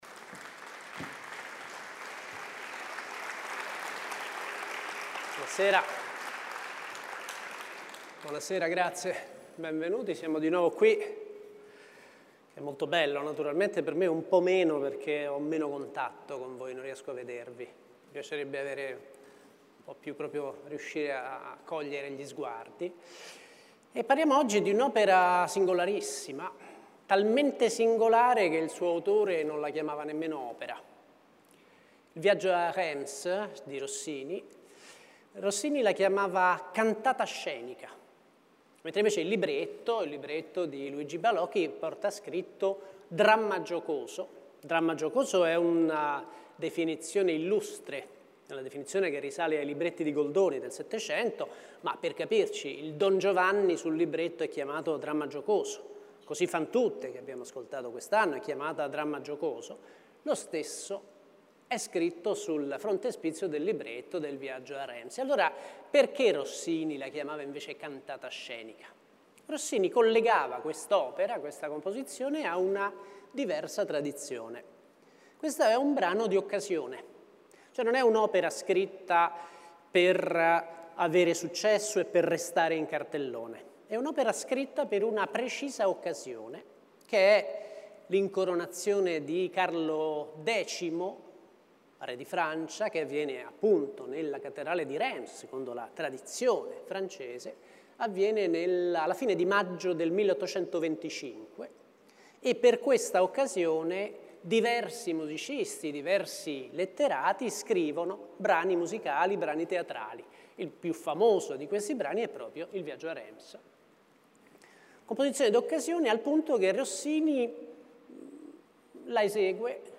Ascola la lezione dedicata a Il viaggio a Reims